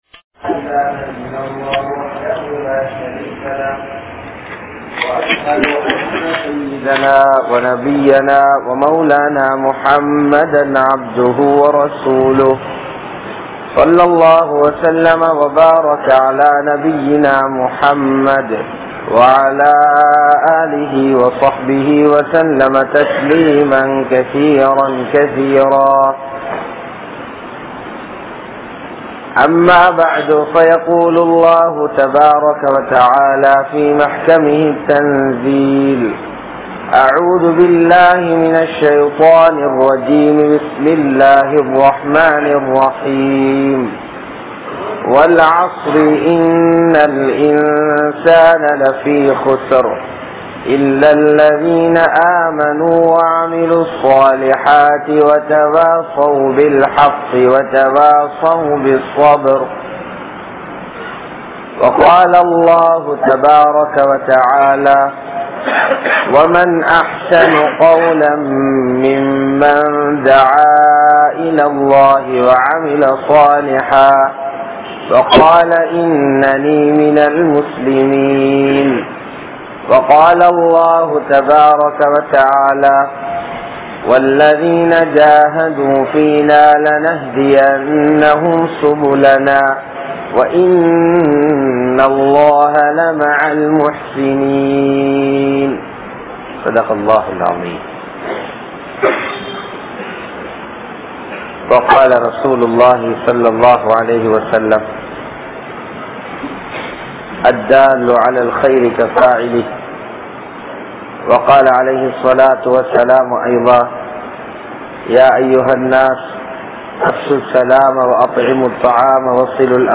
Thiyaaham Seiyaamal Dhauwath Illai (தியாகம் செய்யாமல் தஃவத் இல்லை) | Audio Bayans | All Ceylon Muslim Youth Community | Addalaichenai